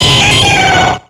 Cri de Galegon dans Pokémon X et Y.